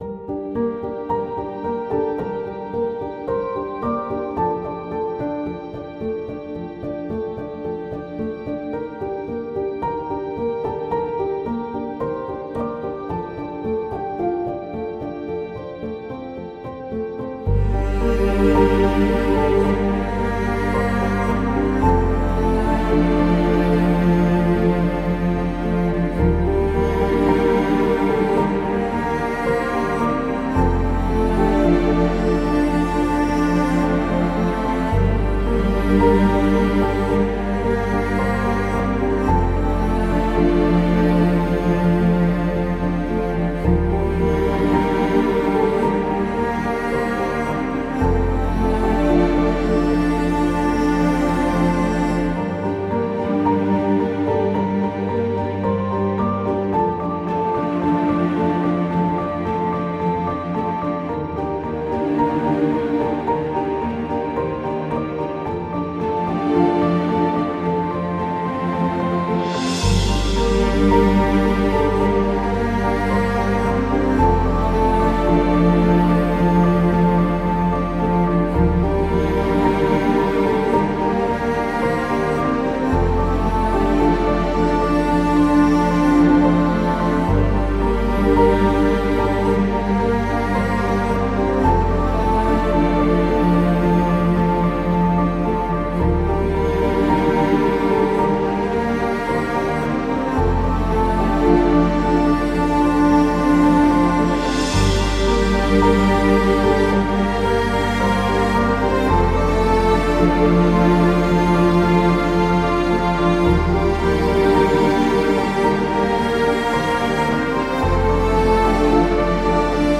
موسیقی اینسترومنتال